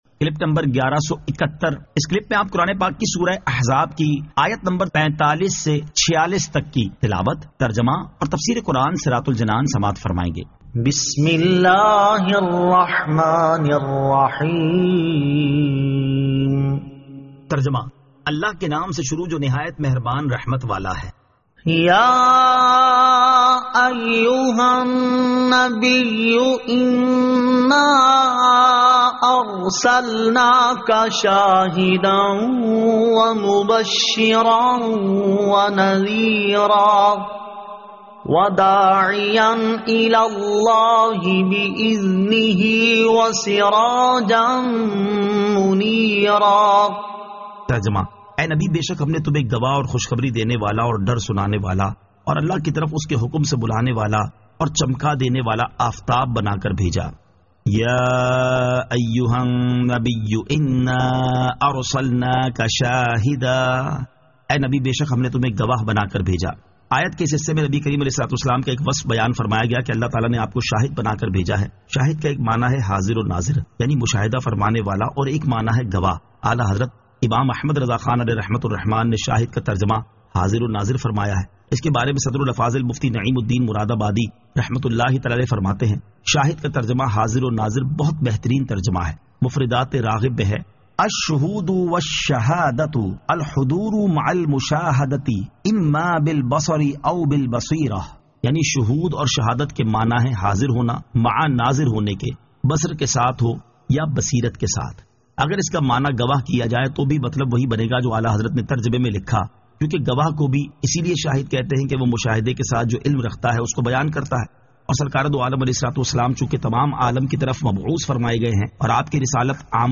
Surah Al-Ahzab 45 To 46 Tilawat , Tarjama , Tafseer